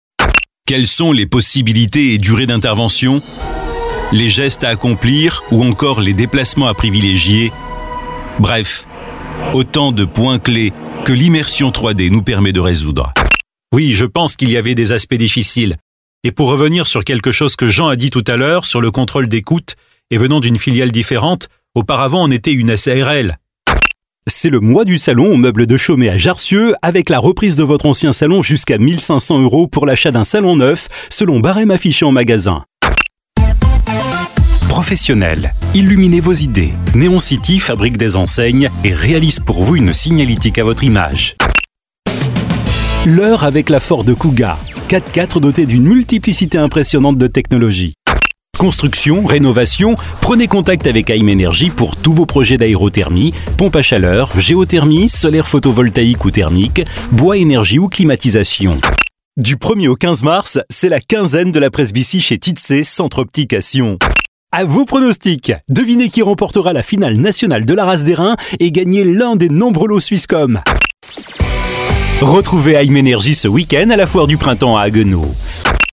Voix Off Médium
Sprechprobe: Sonstiges (Muttersprache):